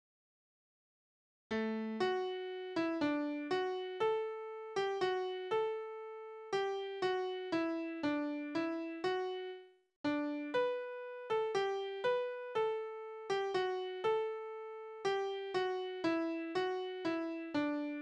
Tonart: D-Dur
Taktart: 4/4
Tonumfang: große None
Besetzung: vokal
Anmerkung: Vortragsbezeichnung: langsam